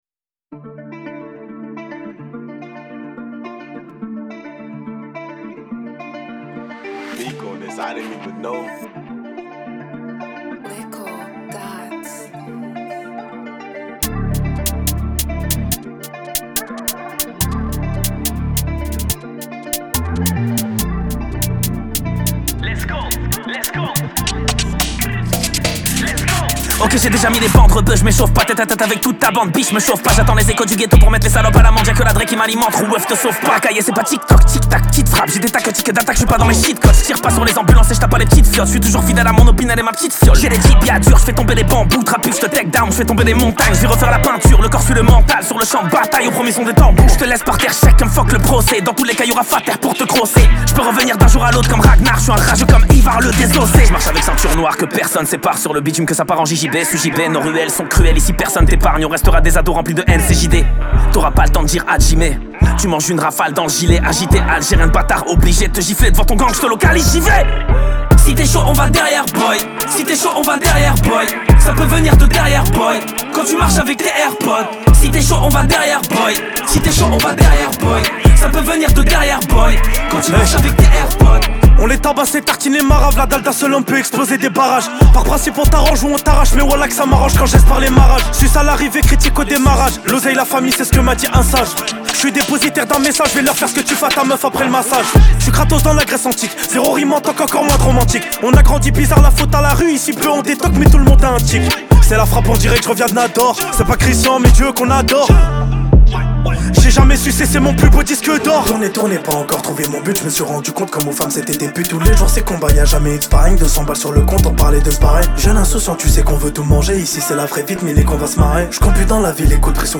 38/100 Genres : french rap, pop urbaine Télécharger